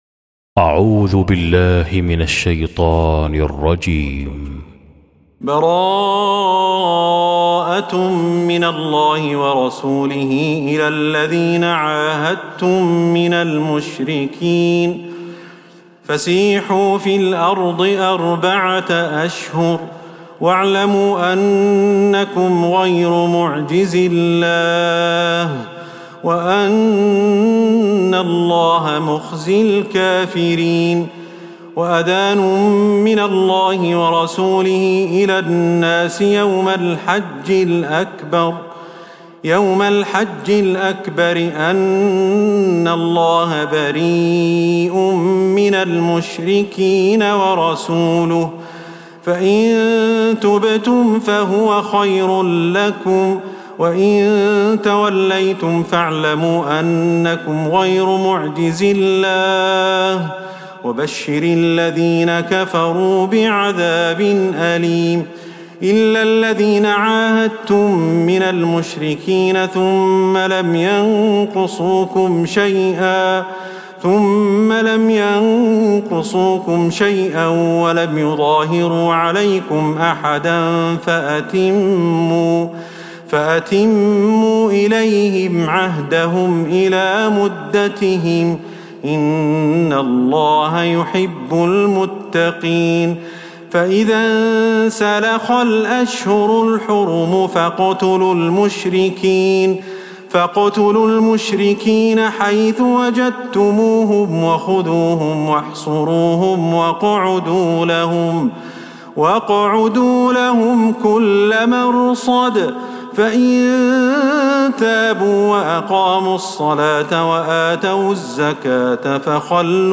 سورة التوبة | Surah At-Tawbah > مصحف تراويح الحرم النبوي عام 1446هـ > المصحف - تلاوات الحرمين